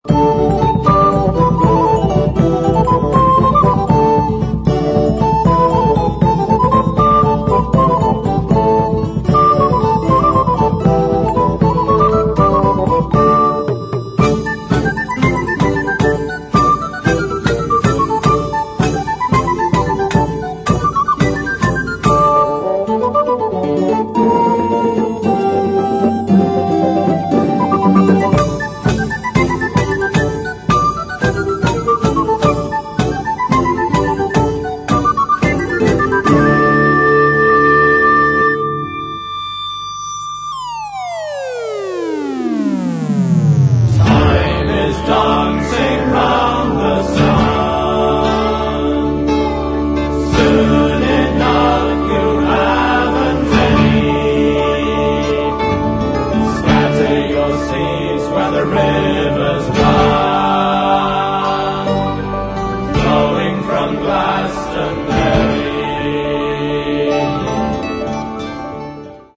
Stereo, 1:09, 56 Khz, (file size: 475 Kb).